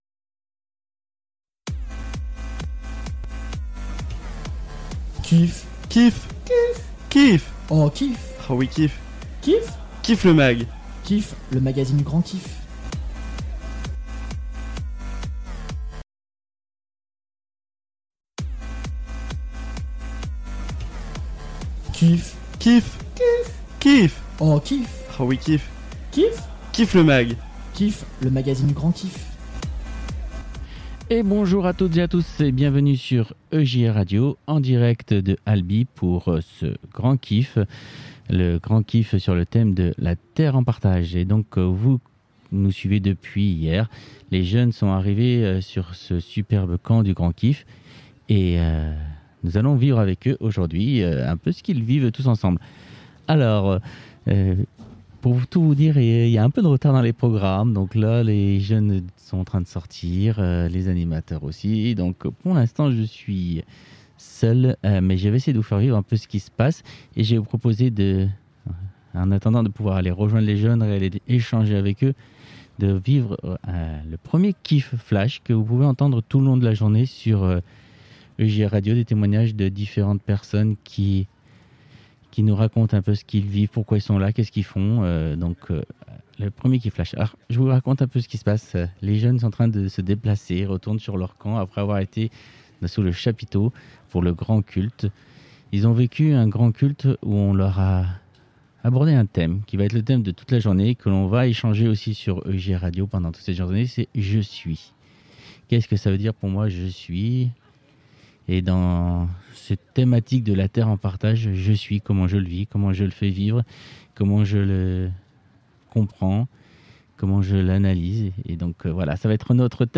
Le 2ème numéro du KIFFMAG en direct de ALBI